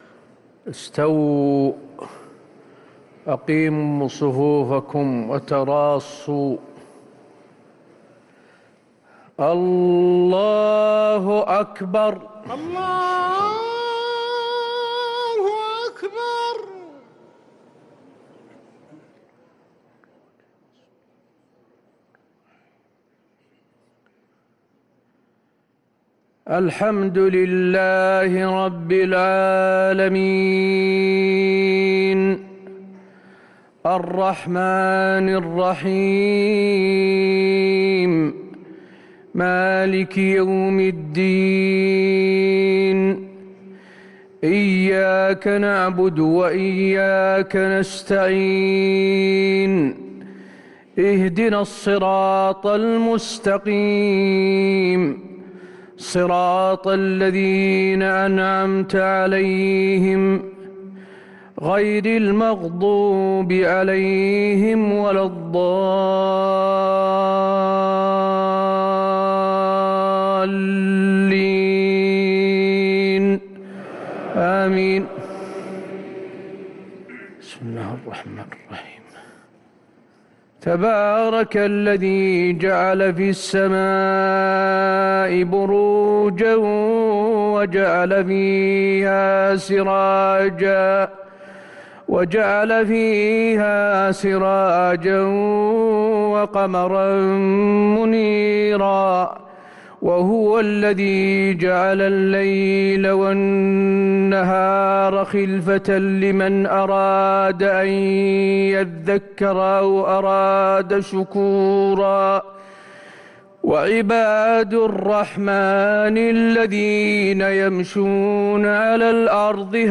صلاة العشاء للقارئ حسين آل الشيخ 7 رمضان 1444 هـ
تِلَاوَات الْحَرَمَيْن .